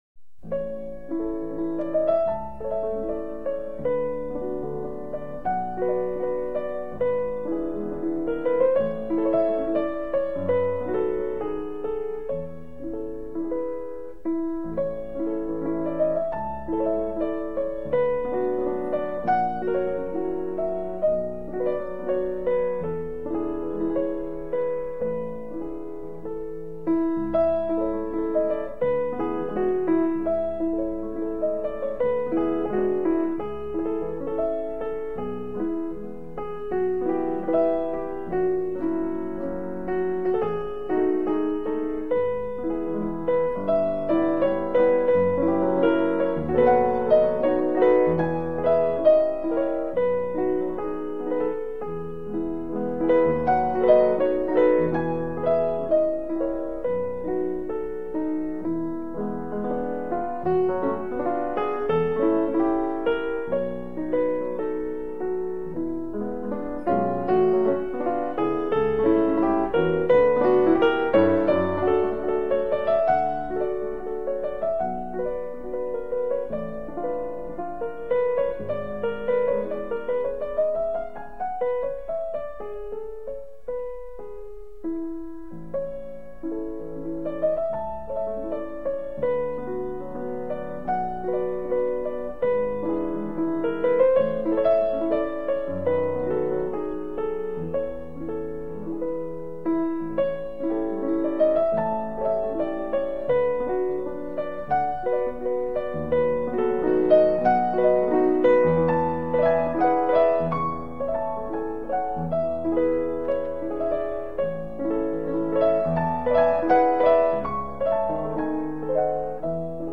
0179-钢琴名曲春之歌.mp3